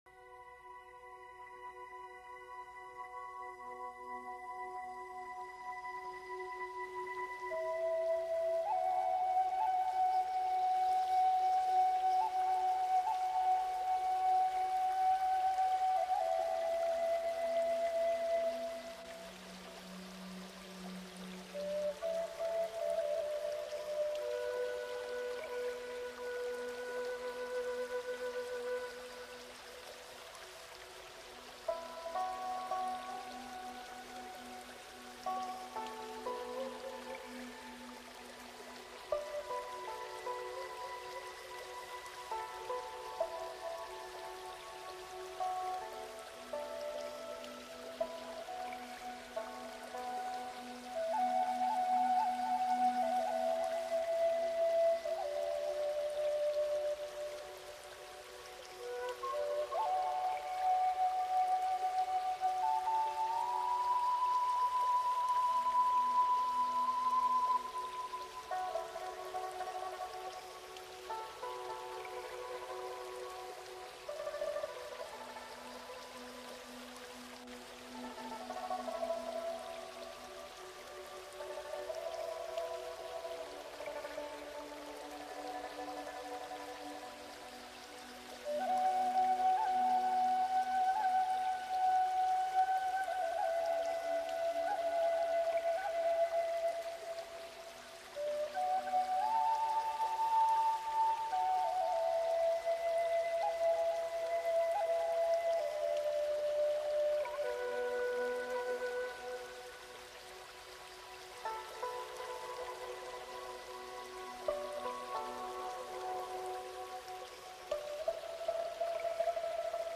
MUSICA RELAJANTE SONIDOS DE LA NATURALEZA CON FLAUTA JAPONESA.mp3